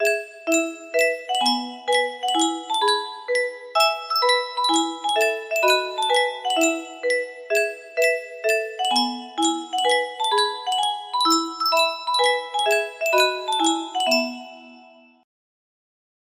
Yunsheng Music Box - Row Row Row Your Boat Y020 music box melody
Full range 60